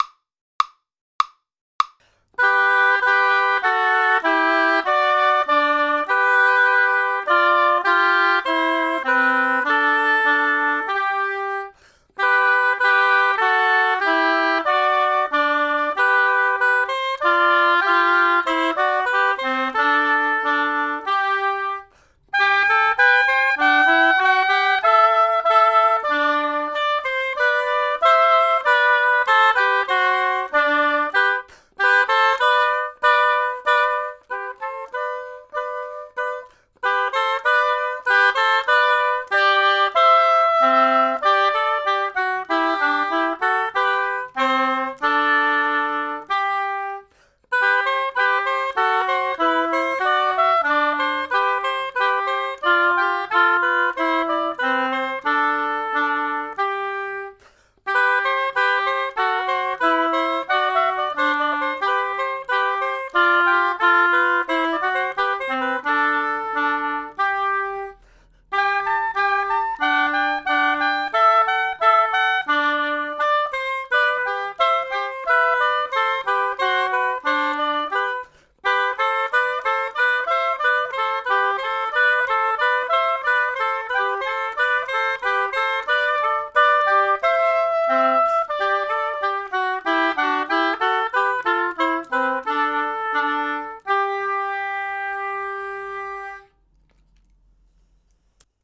duo de hautbois